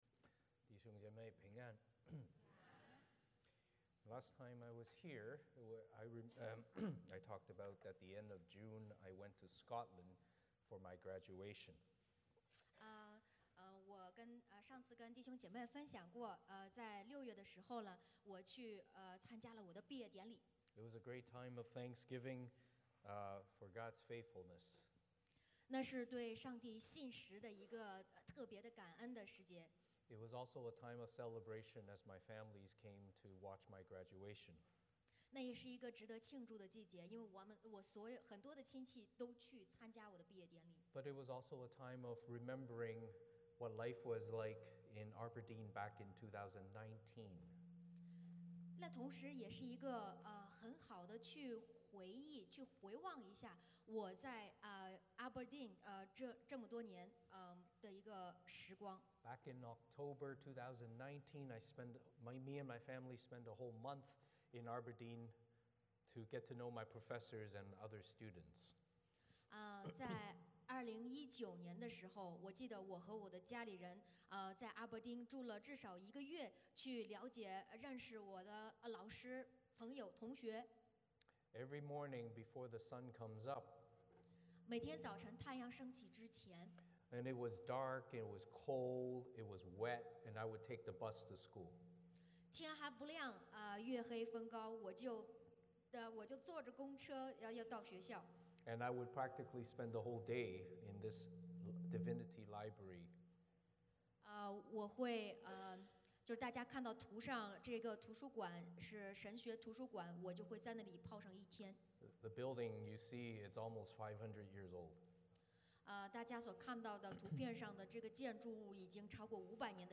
欢迎大家加入我们国语主日崇拜。
1-2 Service Type: 主日崇拜 欢迎大家加入我们国语主日崇拜。